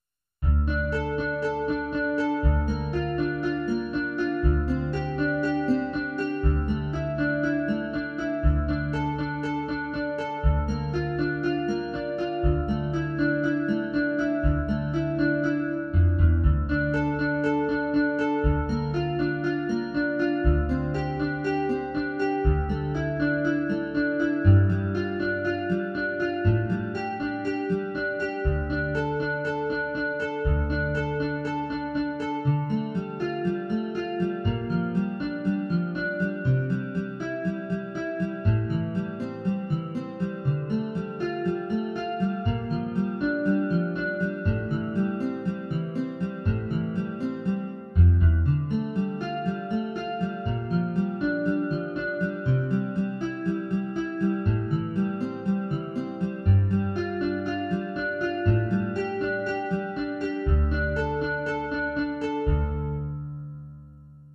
numérisation des versions MIDI sur Roland Soundcanvas